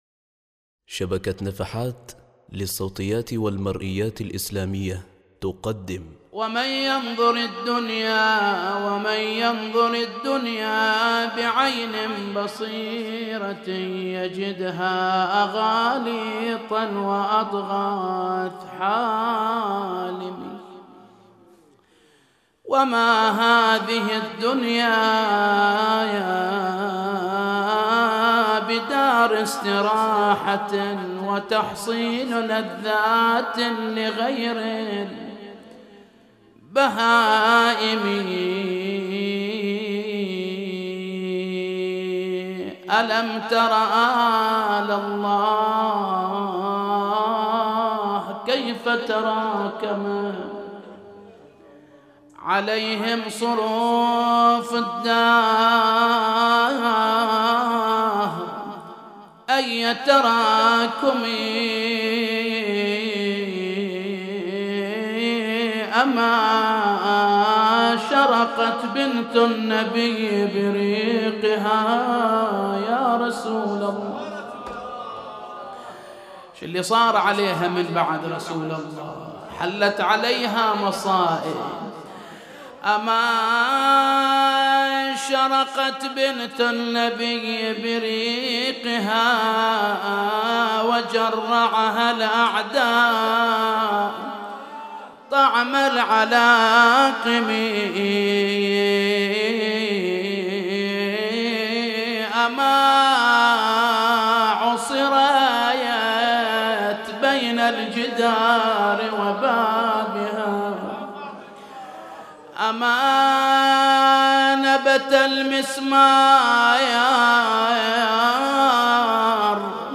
نعي مصاب السيدة فاطمة الزهراء عليها السلام |